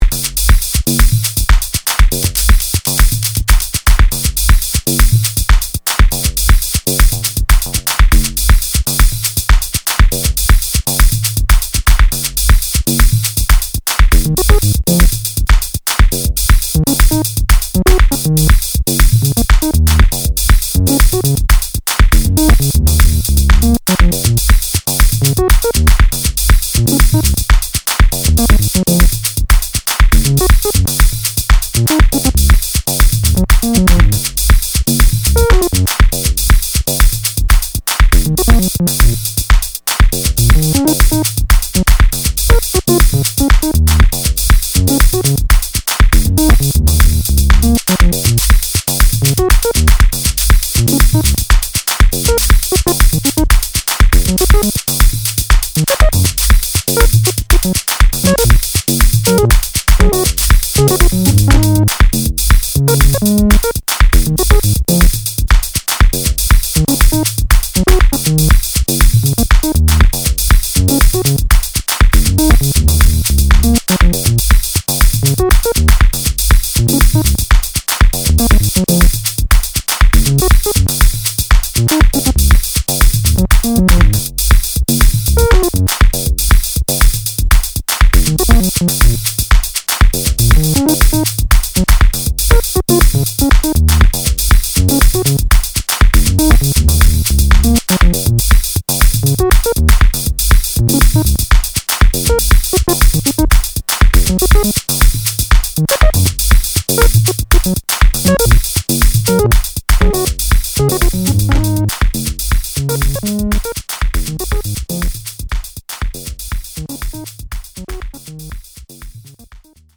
またもやダークな渦に飲み込まれます。